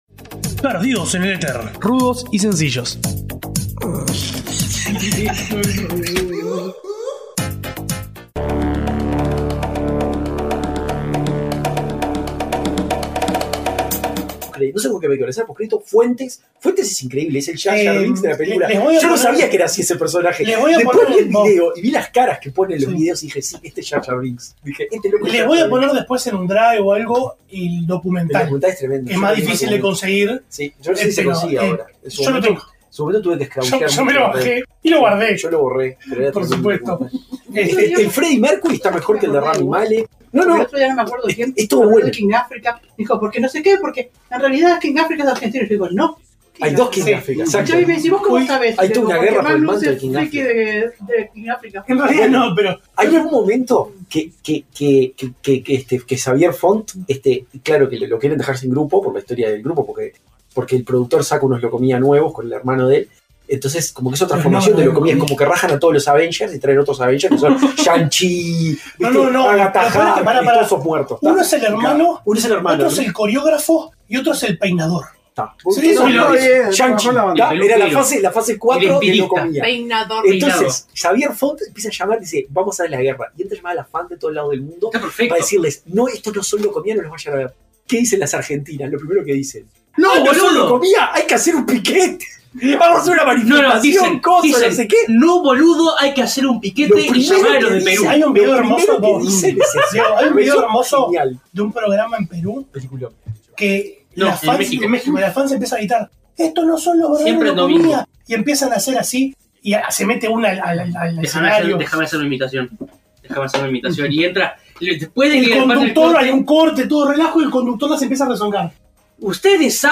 Charlas con el micrófono prendido, que no entraron en el programa de la semana, pero que igual decidimos compartir con ustedes. Hablámos de Locomía, del whisky MacPay, y quizá alguna otra cosa.